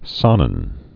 (sänən, zä-)